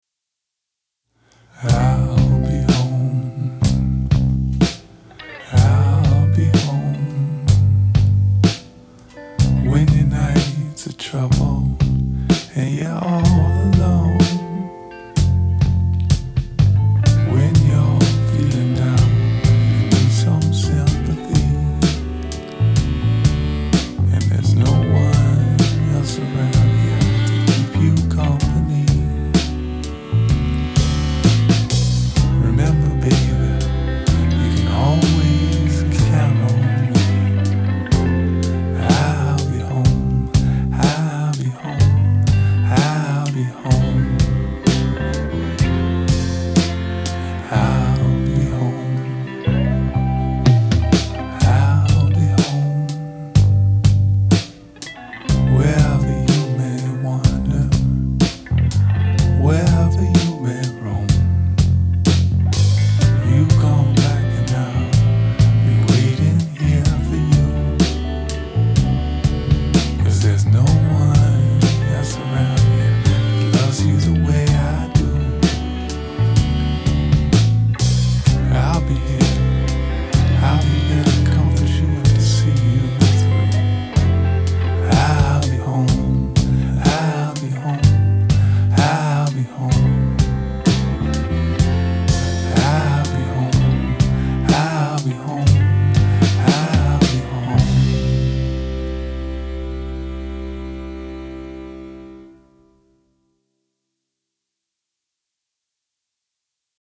informal covers recorded for the blog